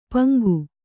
♪ paŋgu